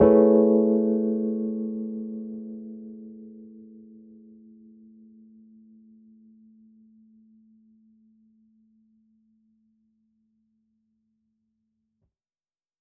Index of /musicradar/jazz-keys-samples/Chord Hits/Electric Piano 3
JK_ElPiano3_Chord-Amaj9.wav